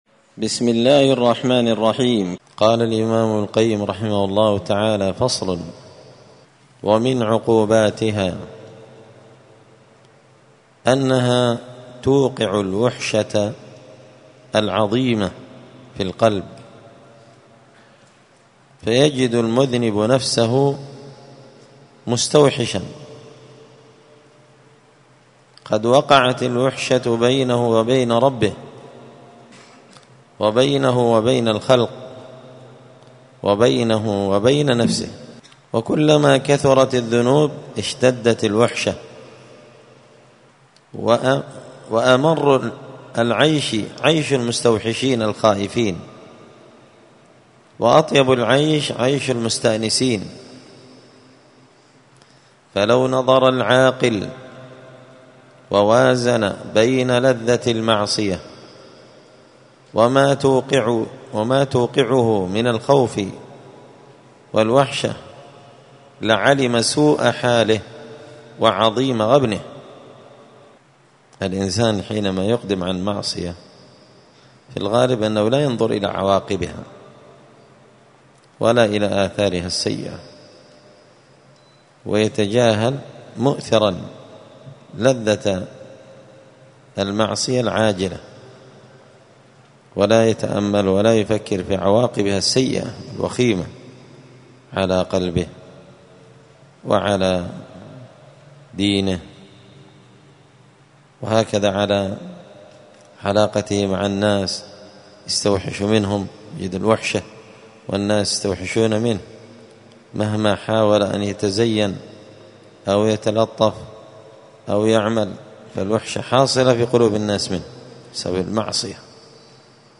*الدرس الخامس والثلاثون (35) فصل من عقوبات الذنوب والمعاصي أنها توقع الوحشة في القلب*